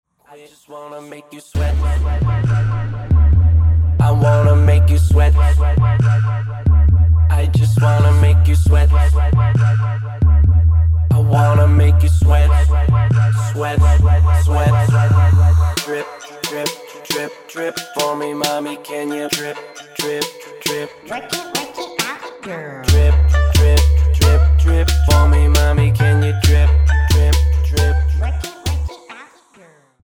W/ Vocals